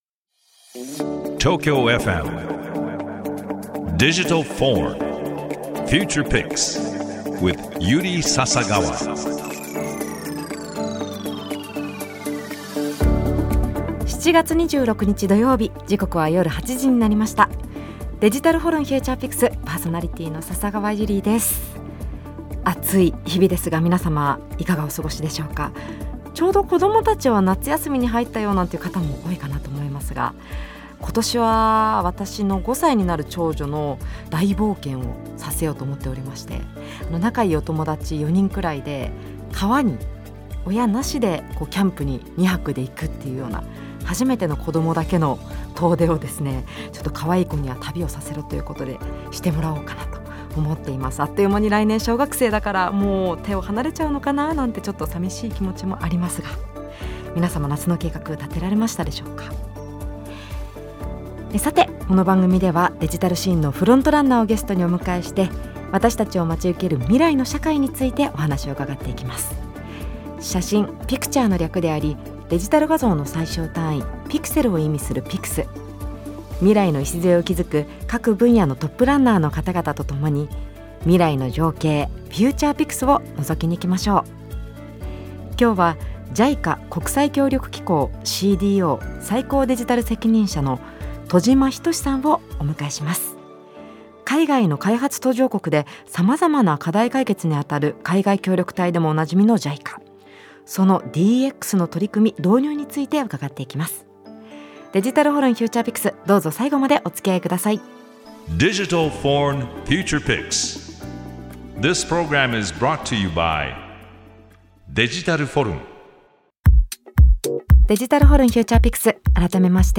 デジタルシーンのフロントランナーをゲストにお迎えして、 私達を待ち受ける未来の社会についてお話を伺っていくDIGITAL VORN Future Pix。